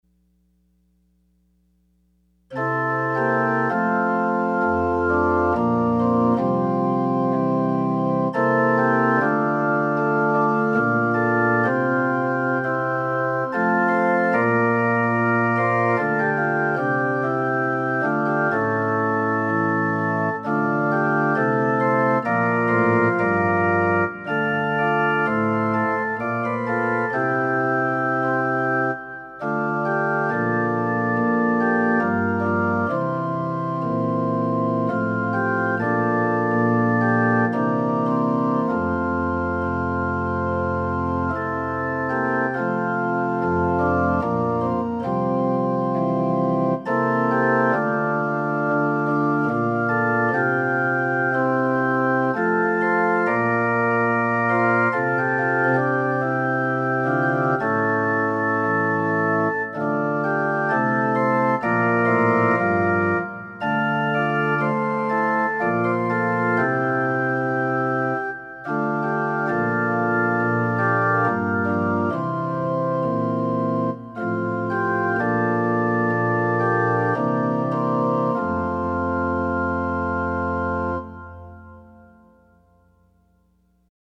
Offering Hymn – Let the broken ones be healed